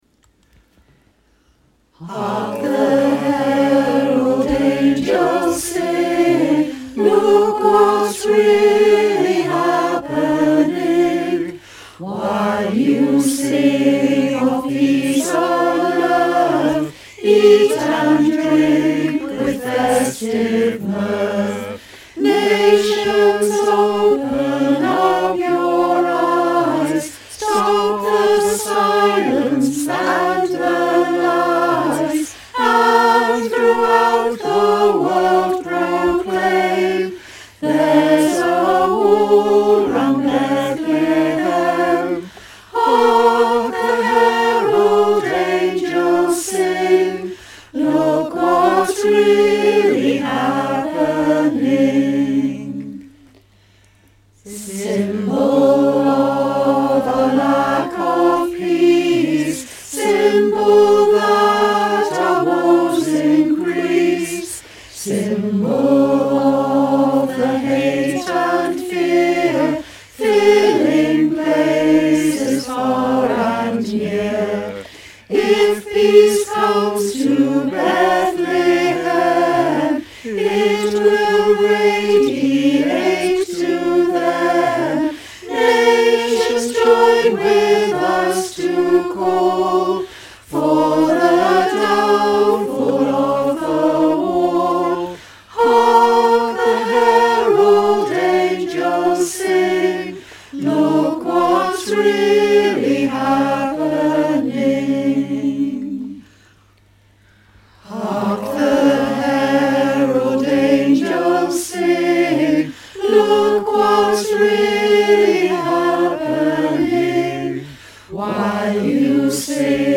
In December 2020 and January 2021, choir members recorded 5 adapted carols in support of the Liverpool Friends of Palestine.